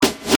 When it’s looking good, select all with Ctrl+A and copy and paste the audio with Ctrl+C and Ctrl+V. Now you’ll have two copies of the drum hit.
Press Alt+Left Arrow to reverse the selection.